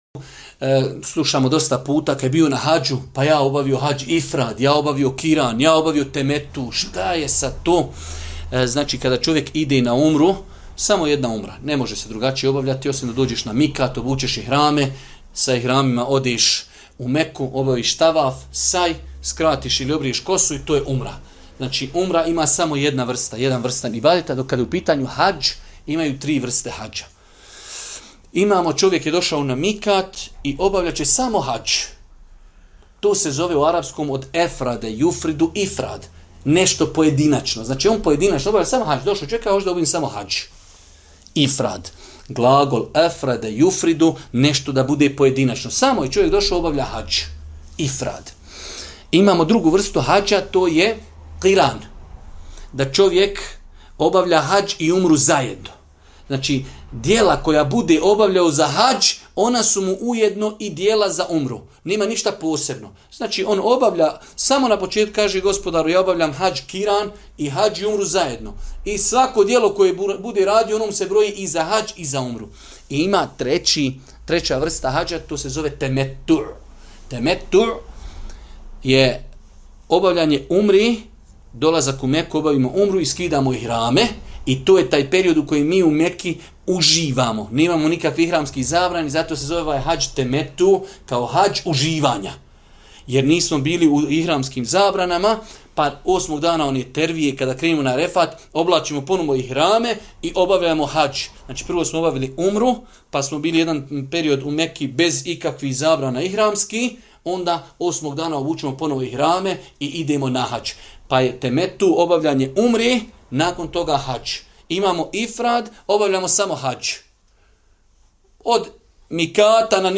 Odgovor prenosimo iz serijala predavanja o pravnim propisima hadža